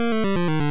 snd_fall.wav